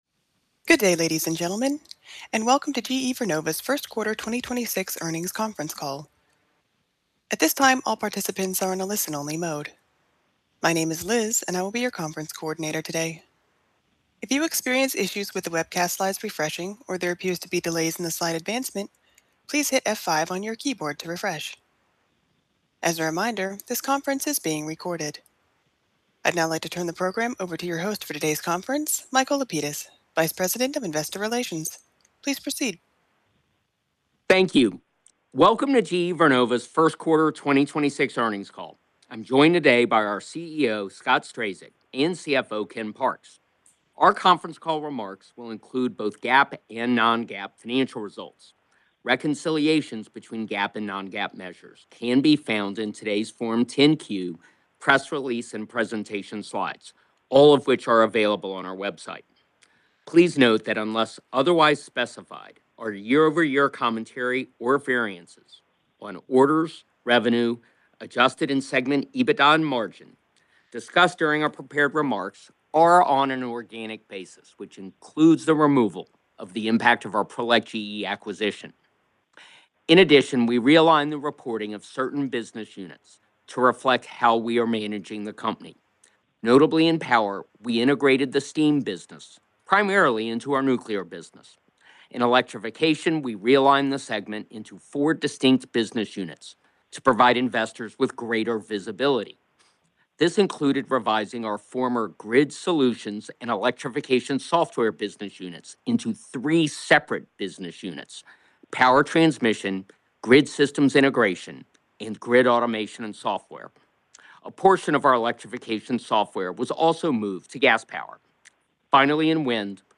Watch the GE Vernova Earnings Report Webcast for the 1st quarter of 2026, which will be presented live on April 22nd from 07:30 am - 08:30 am EDT by the GE Vernova leadership team.